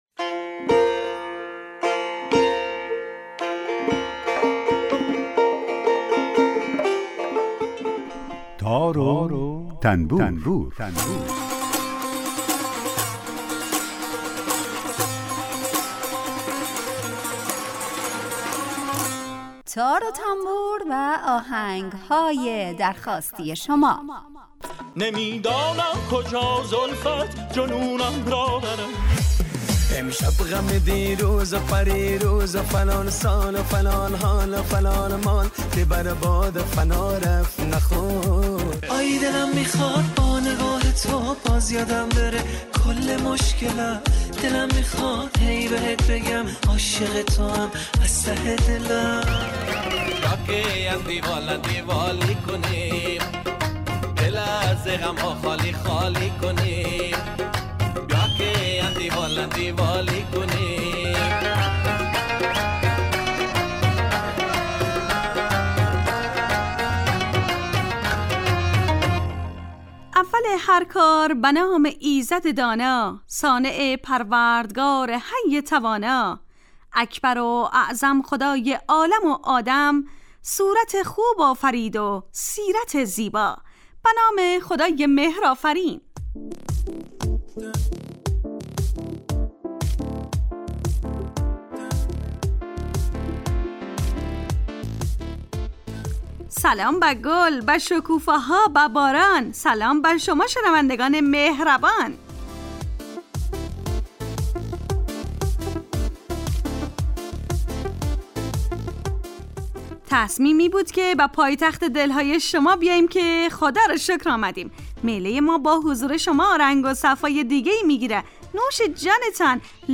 آهنگهای درخواستی